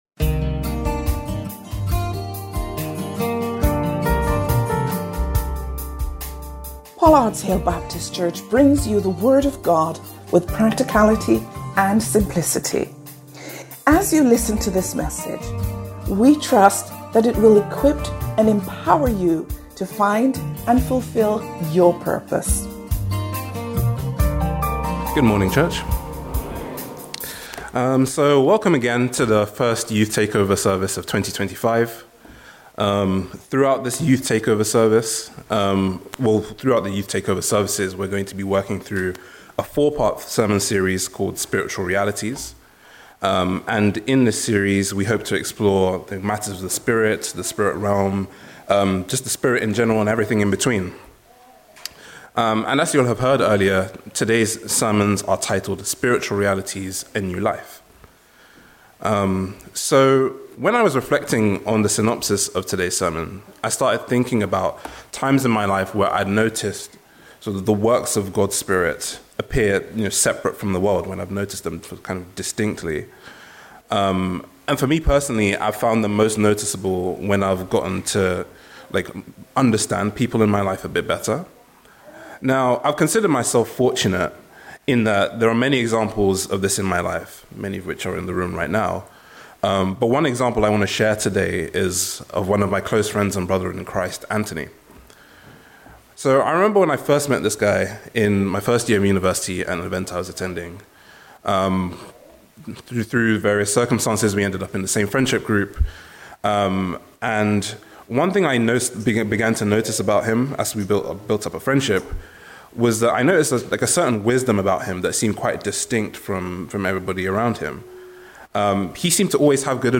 Join us once again, for a fresh perspective, as our young people bring their unique talents and passions to every aspect of the worship service, stepping up and take the lead!